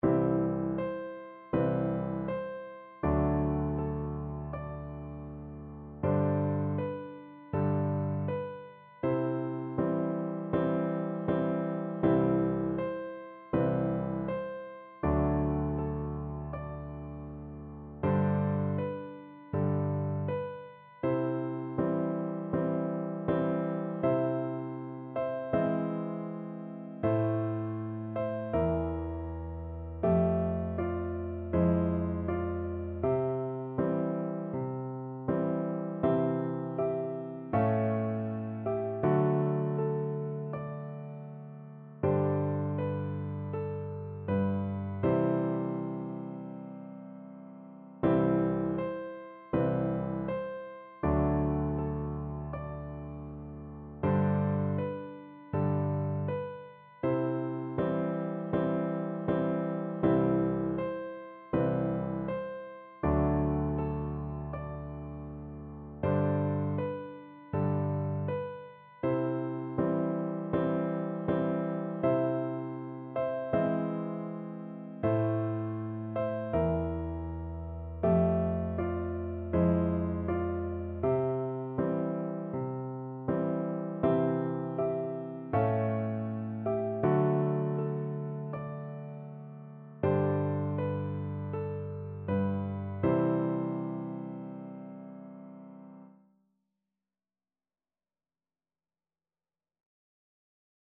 C major (Sounding Pitch) (View more C major Music for Piano Duet )
Andante = c. 80
4/4 (View more 4/4 Music)
Piano Duet  (View more Easy Piano Duet Music)
Traditional (View more Traditional Piano Duet Music)